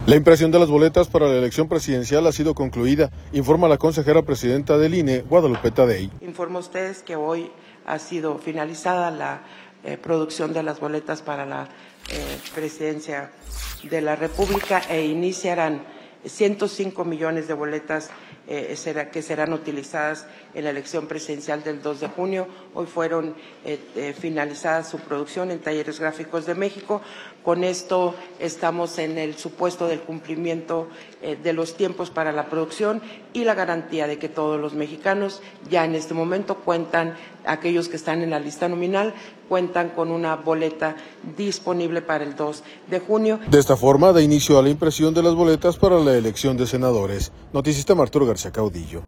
La impresión de las boletas para la elección presidencial ha sido concluida, informa la consejera presidenta del INE, Guadalupe Taddei.